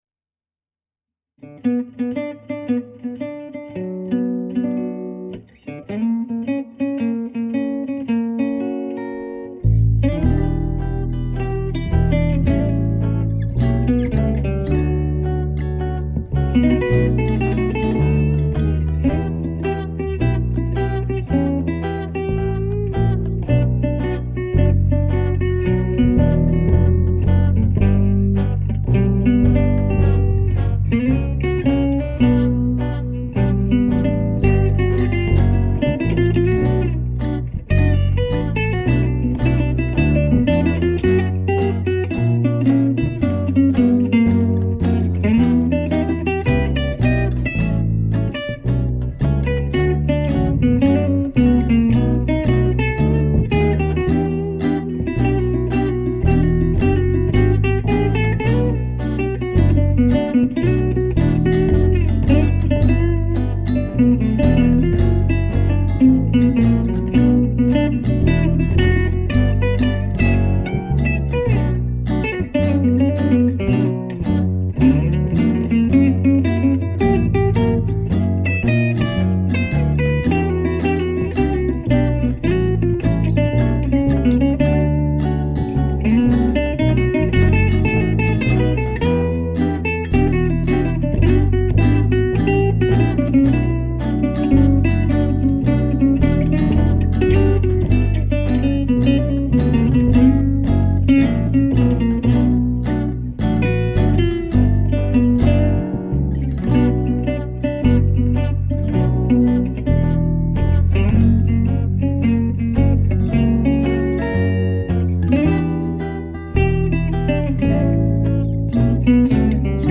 Enregisté au Studio Angström fin 2008